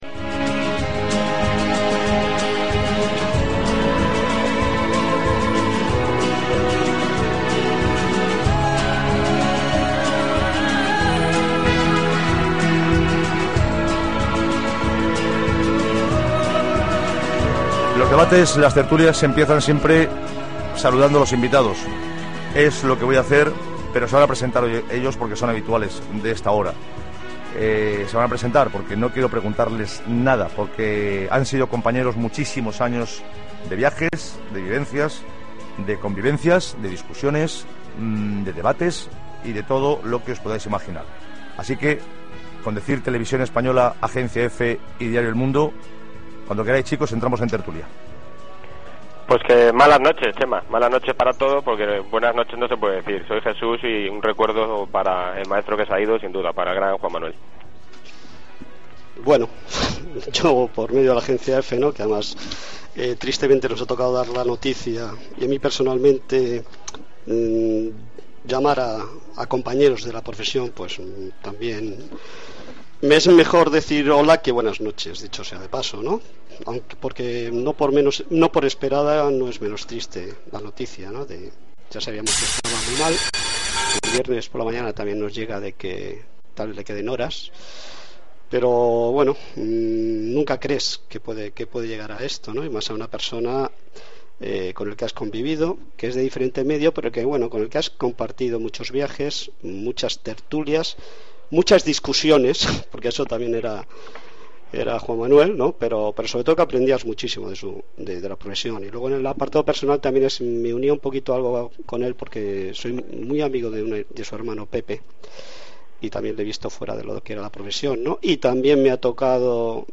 Tertúlia del programa